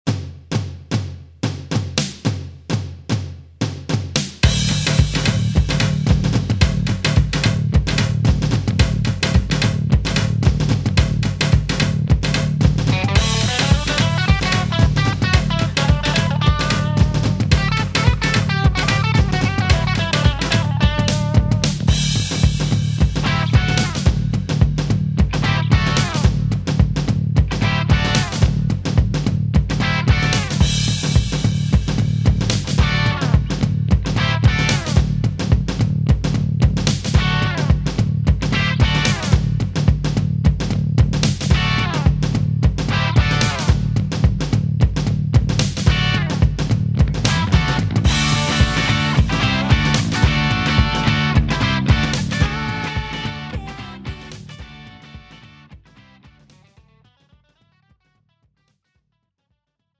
축가 및 결혼식에 최적화된 고품질 MR을 제공합니다!